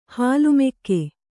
♪ hālu mekke